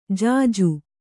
♪ jāju